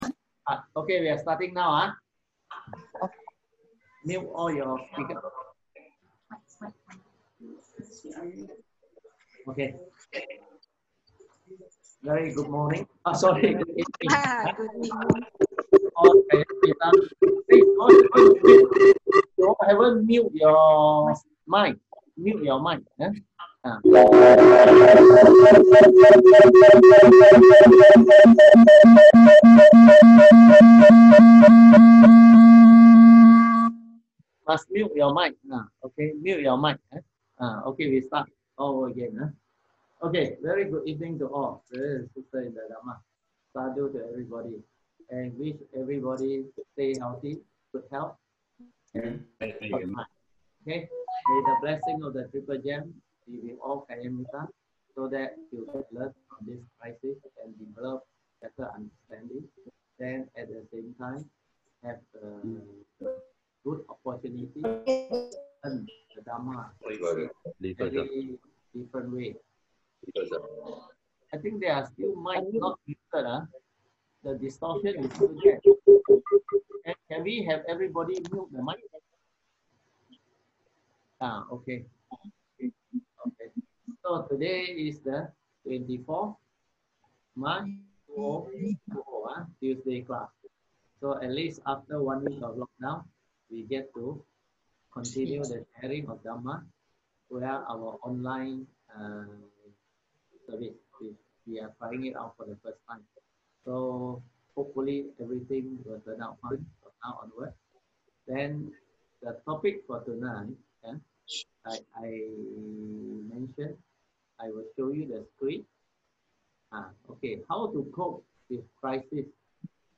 Tuesday Class